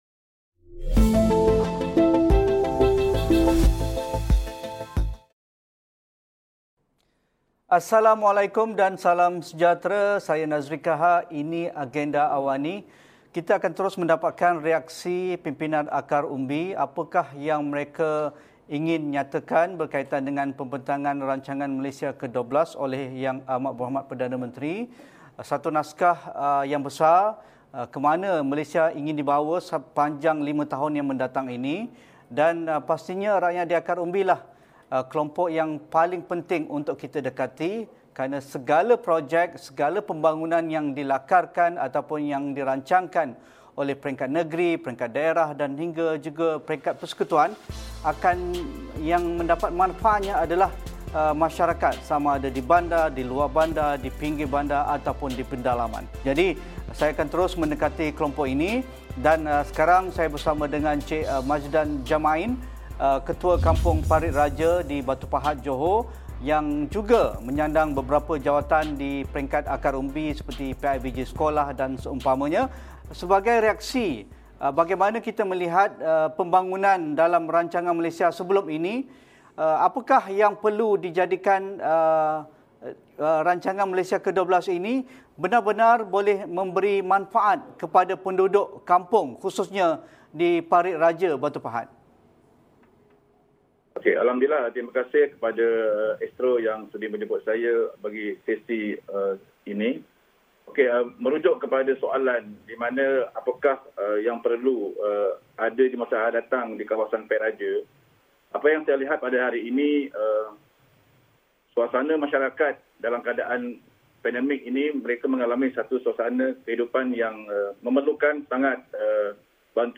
Apa cabaran bagi melaksanakan transformasi luar bandar khususnya dalam tempoh lima tahun akan datang? Jurang pembangunan dan pendapatan makin melebar, apa cabaran untuk sektor pekerjaan di luar bandar berkembang? Diskusi 8.30 malam.